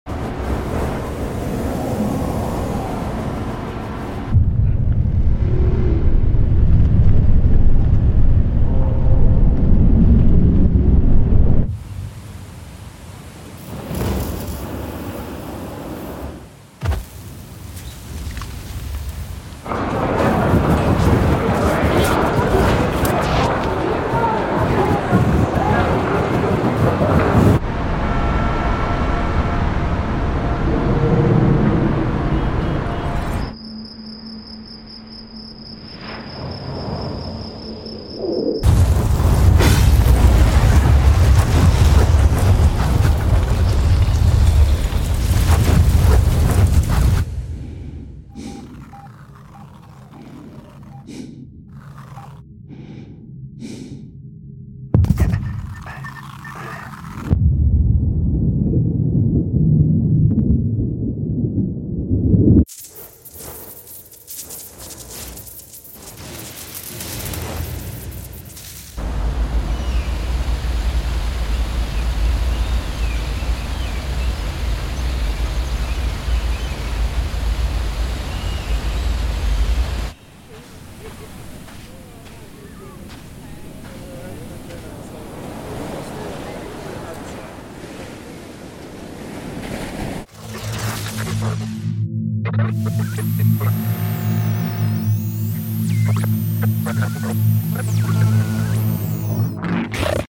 Transform any footage with immersive sound design using Krotos Studio. From subtle textures to cinematic hits - it’s all created fast using real-time, drag-and-drop layers.